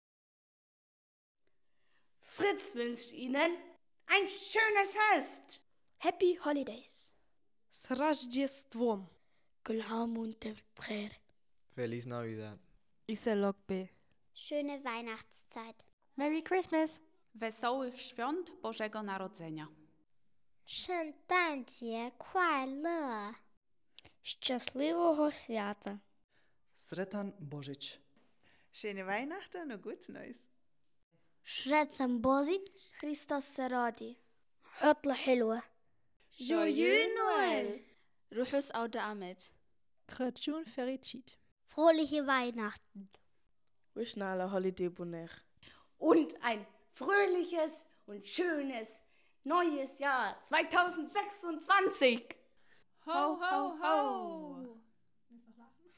Hier finden Sie eine Sprachnachricht, in der wir in den verschiedenen Sprachen der friz-Familien eine schöne Weihnachtszeit, erholsame Ferien und einen guten Start ins neue Jahr wünschen. Uns war es in diesem Jahr ein besonderes Anliegen, die kulturelle Vielfalt unserer Familien aufzugreifen und unsere guten Wünsche in vielen Sprachen hörbar zu machen.